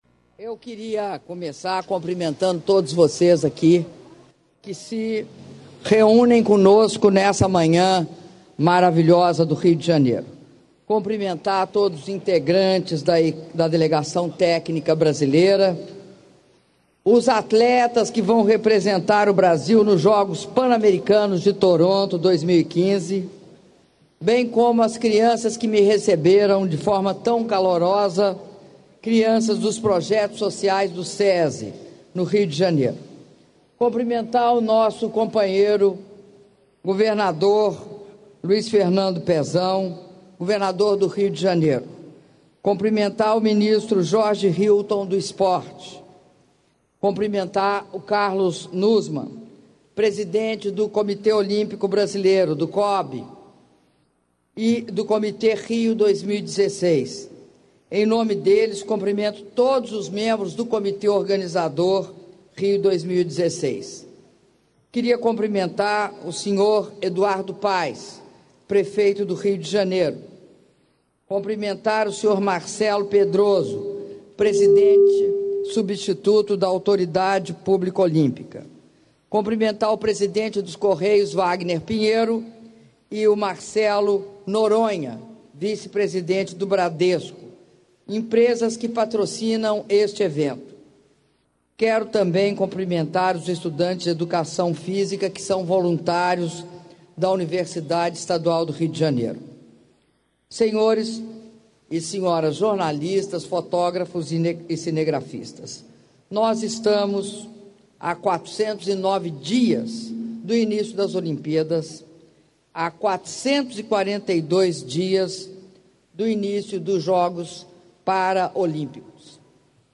Áudio do discurso da Presidenta da República, Dilma Rousseff, na cerimônia de comemoração do Dia Olímpico - Rio de Janeiro/RJ (06min22s)